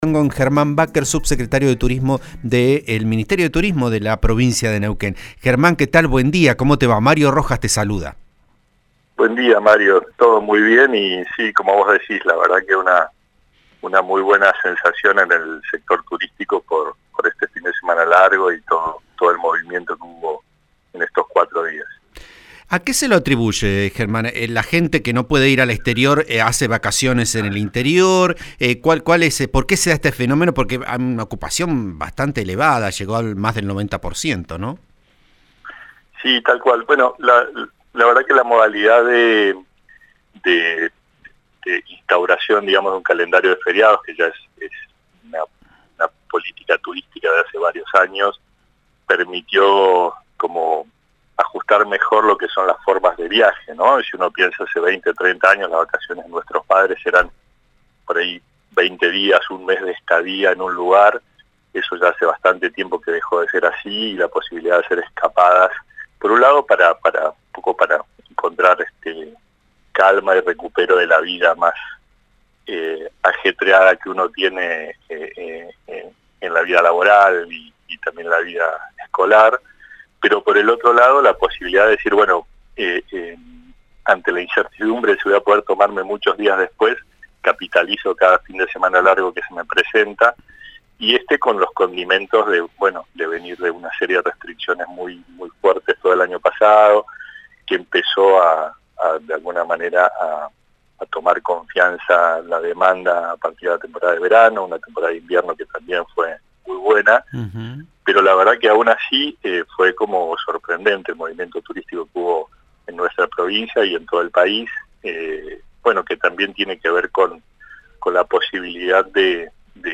Escuchá la entrevista al subsecretario de Turismo, Germán Bakker, en Vos A Diario por RN Radio (89.3):